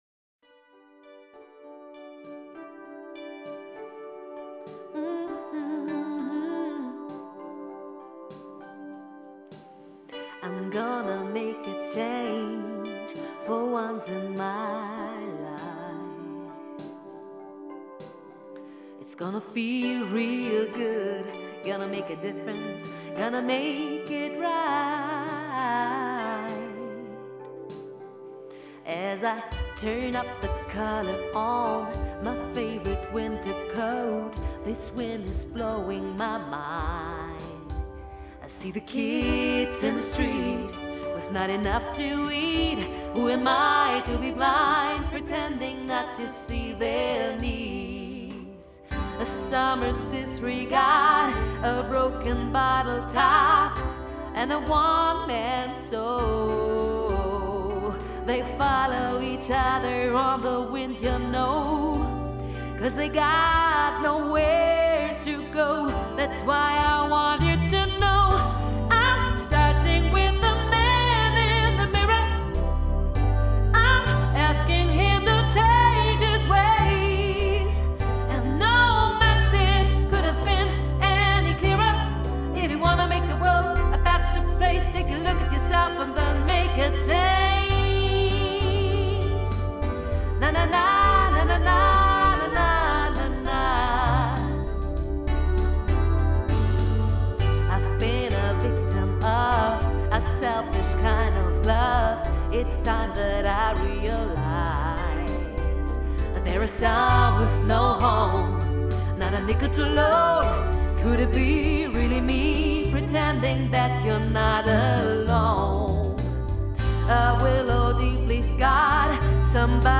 Professionelle Live Musik für hochwertige Veranstaltungen.